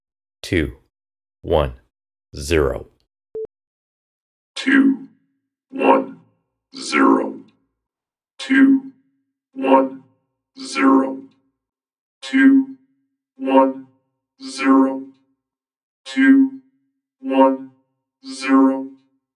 Stereotypical robot voice can be obtained using vocoder and/or comb effects …